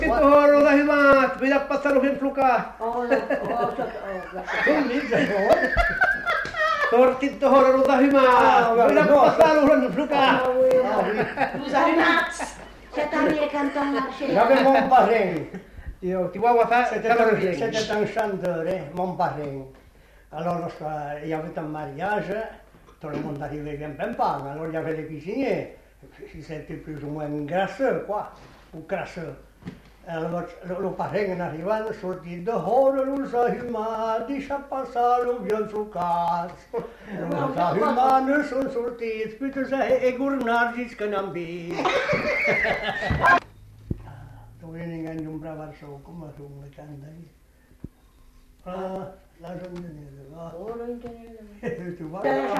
Aire culturelle : Agenais
Genre : chant
Effectif : 1
Type de voix : voix d'homme
Production du son : chanté